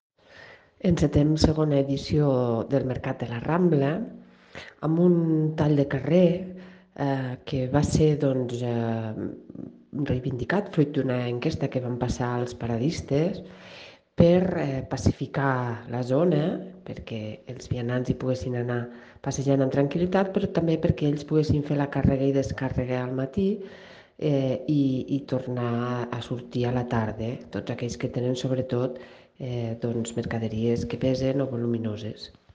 Tall de veu de la regidora Marta Gispert
tall-de-veu-de-la-regidora-marta-gispert